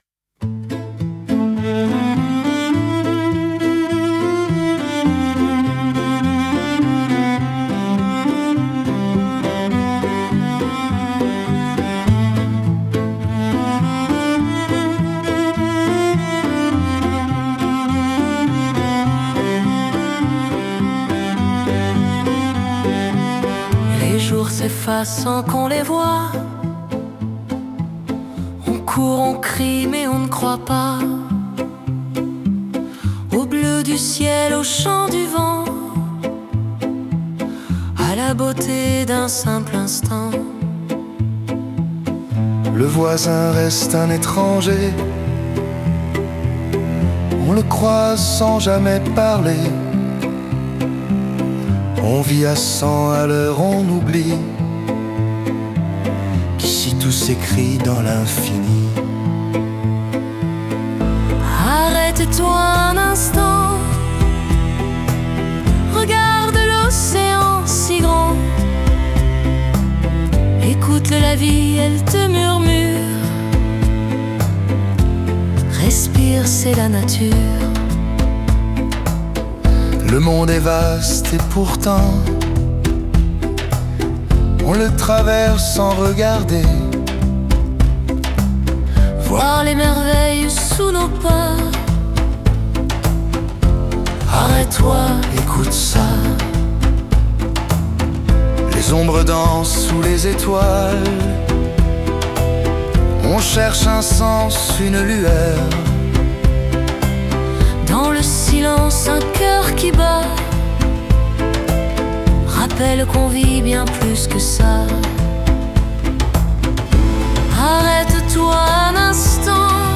Montage et arrangement musical : Suno